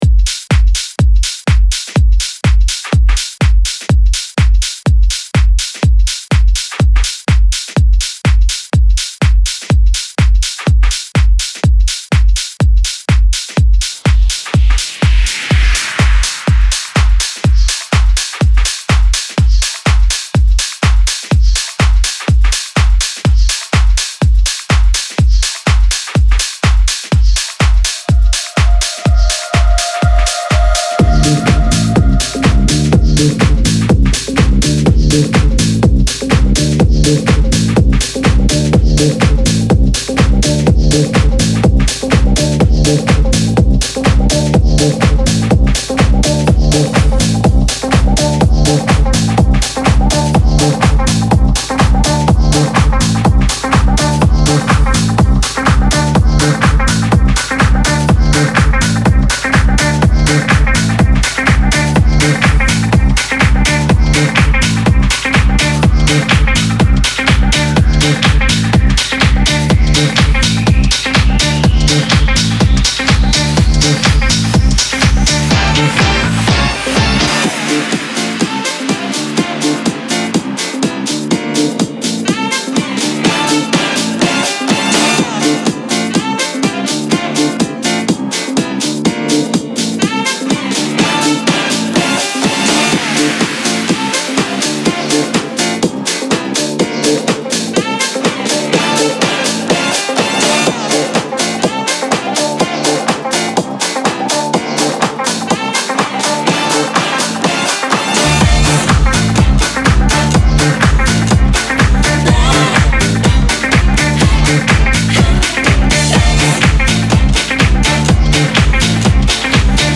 Jackin House Mix